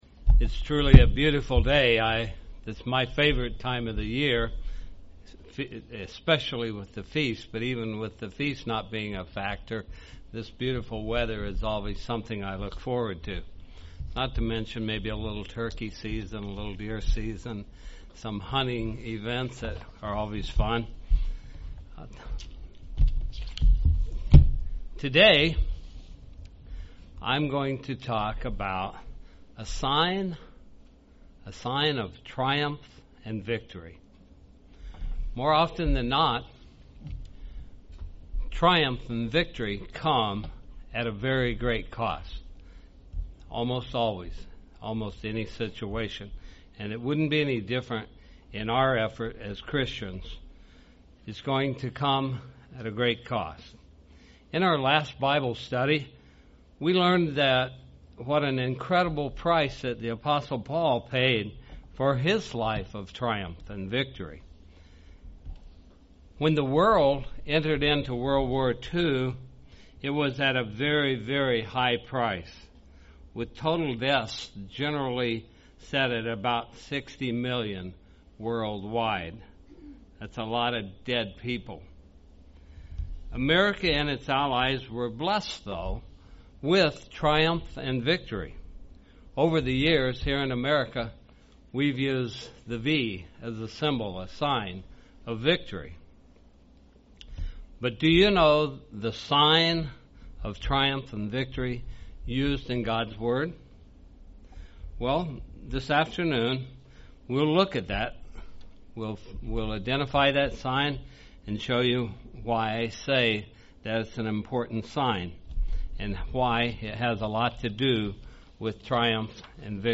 UCG Sermon Studying the bible?
Given in Springfield, MO